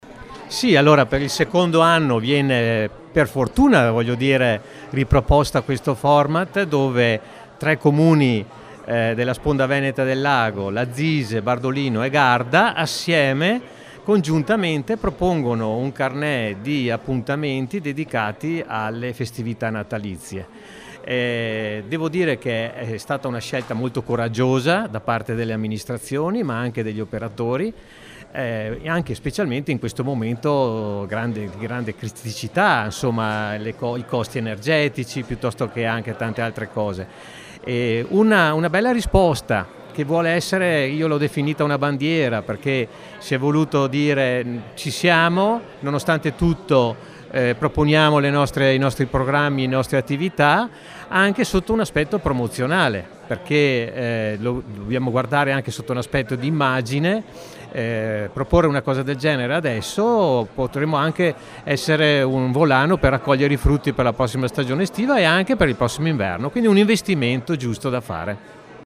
Al nostro microfono Lauro Sabaini, sindaco di Bardolino